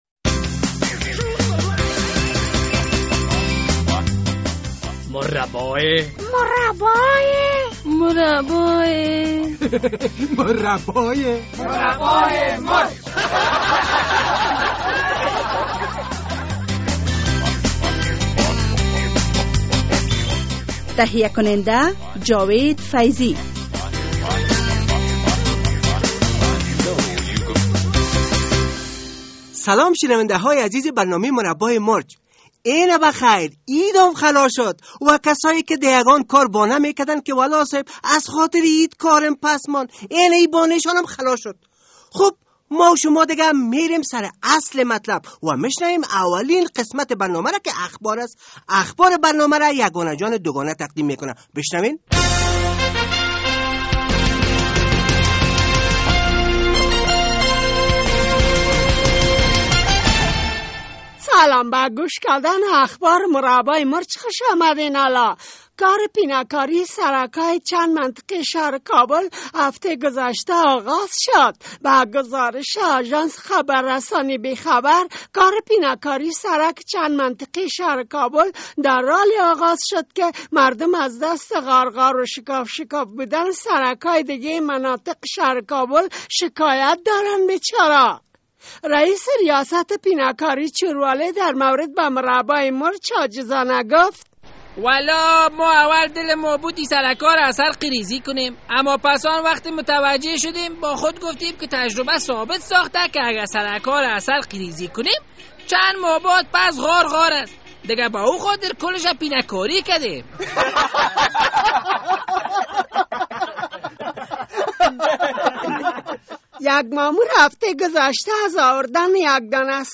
همان طور که خودتان می فامین نصف وکیل های شورا یانی پنجاه فیصدش به حج رفتن و چوکی های شان ده شورا ده ای روز ها همطور خالی است. به همی خاطر ای هفته با یک تن از وکیل صاحبای که شورا ره ترک گفته یک مصاحبه تلیفونی...